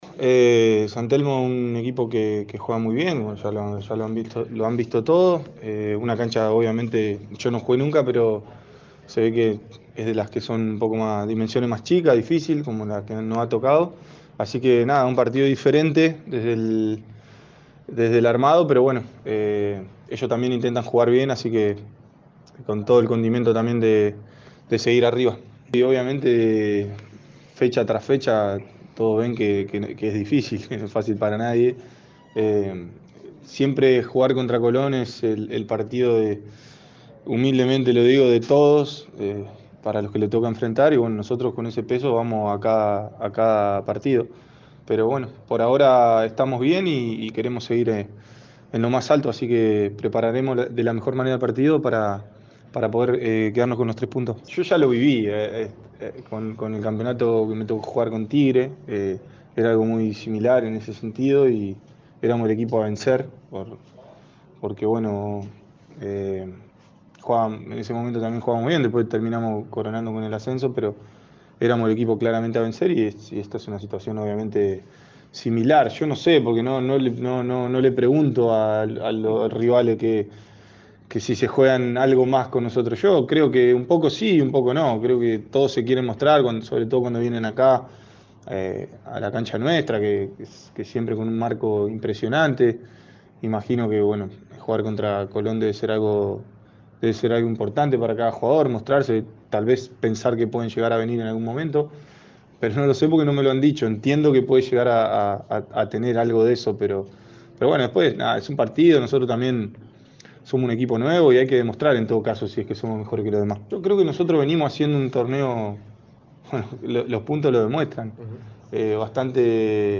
Javier Toledo primero y Sebastián Prediger después, fueron los dos futbolistas de Colón que brindaron una conferencia de prensa luego de la práctica de esta mañana.